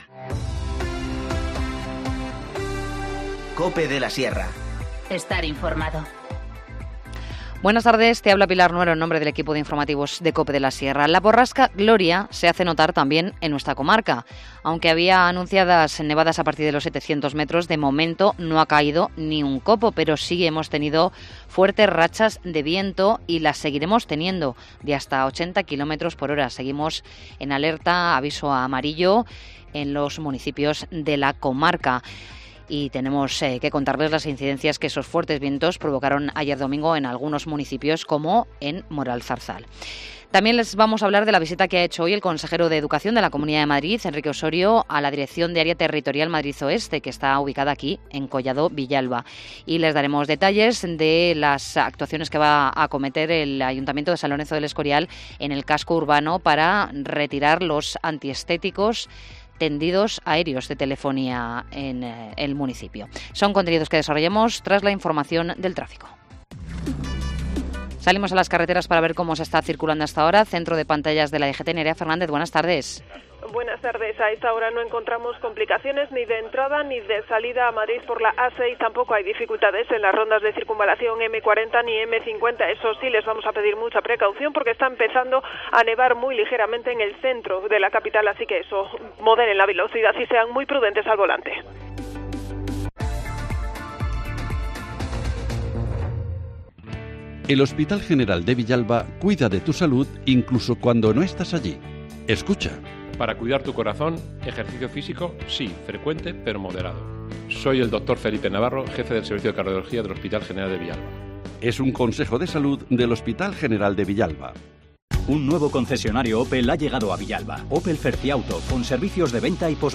Informativo Mediodía 20 enero 14:20h